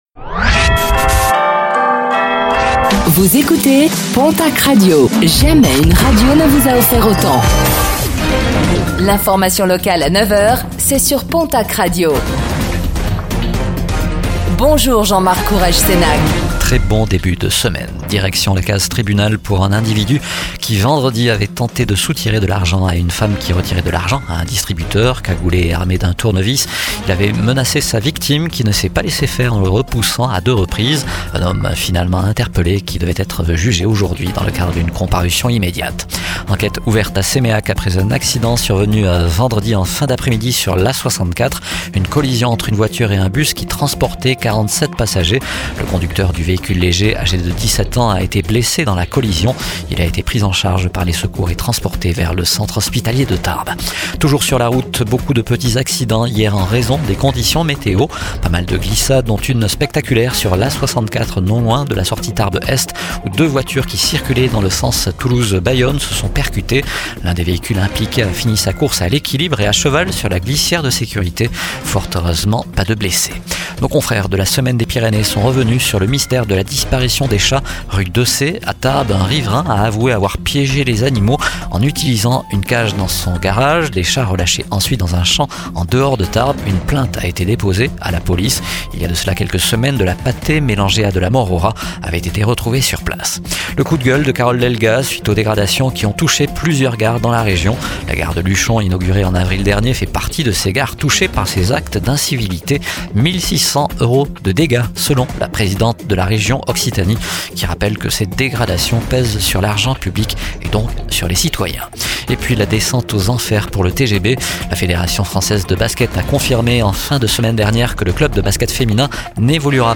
09:05 Écouter le podcast Télécharger le podcast Réécoutez le flash d'information locale de ce lundi 22 septembre 2025